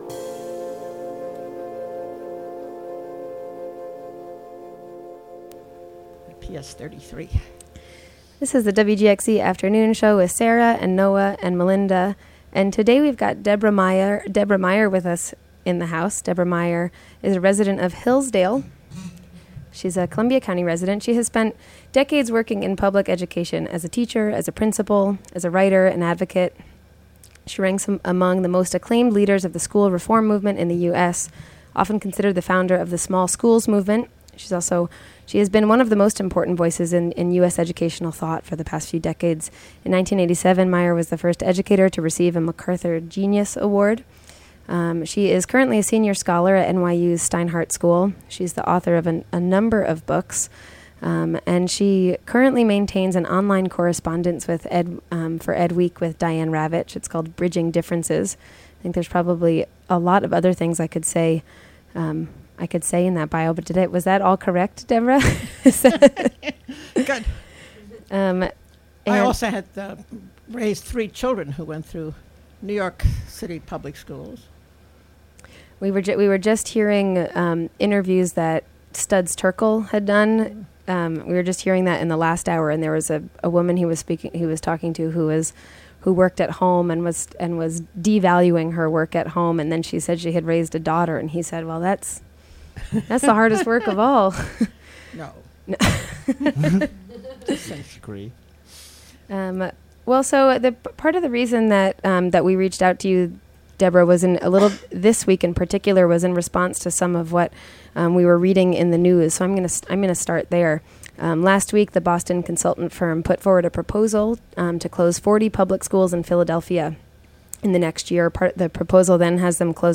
In this interview, we talk about the charterization and privatization of public education in America, the small school movement, localism and decision-making, the re-segregation of public schools, and Deborah's own schools: Mission Hill in Boston and Central Park East in East Harlem.